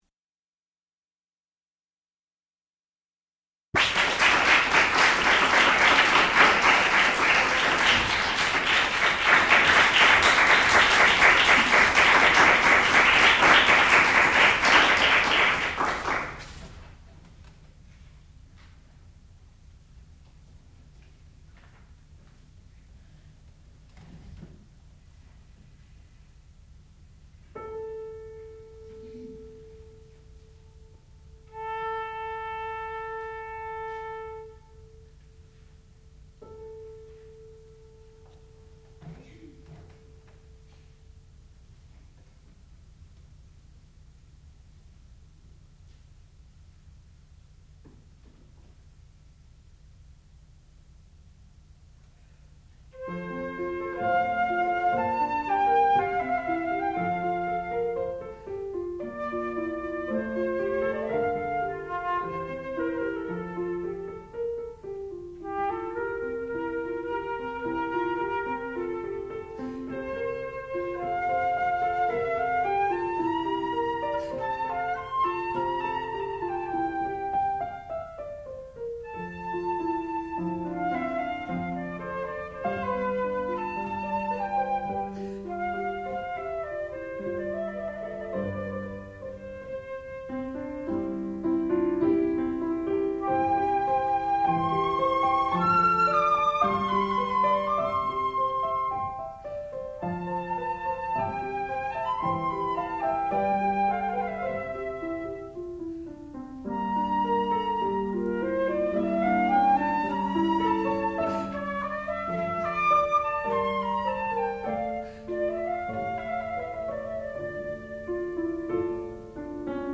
Sonata for flute and piano
sound recording-musical
classical music
Advanced Recital